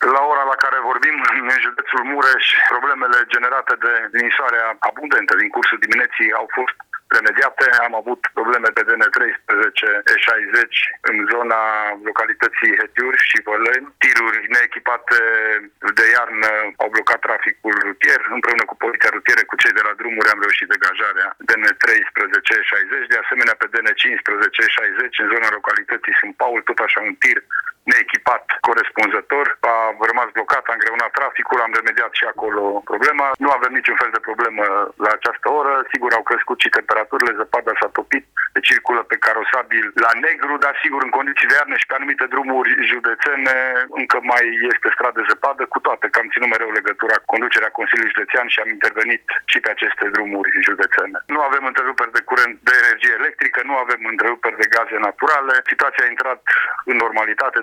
Prefectul județului Mureș, Lucian Goga, a declarat că la această oră nu mai sunt probleme deosebite în trafic și nici întreruperi de gaze și energie electrică: